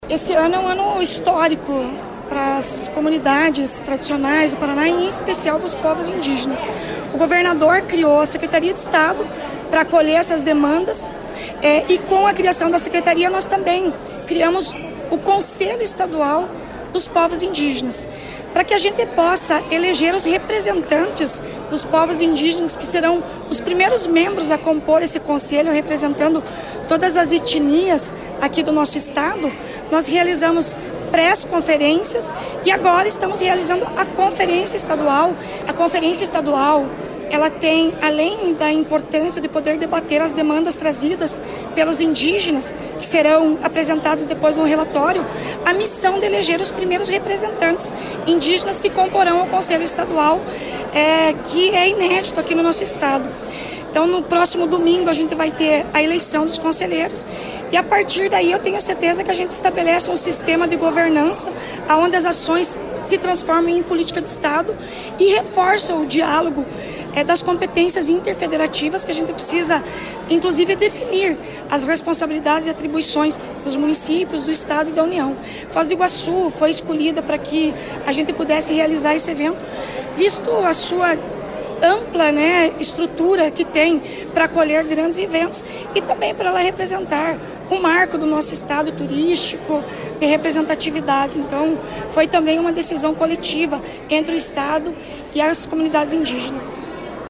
Sonora da secretária da Mulher, Igualdade Racial e Pessoa Idosa, Leandre Dal Ponte, sobre a Primeira Conferência dos Povos Indígenas do Paraná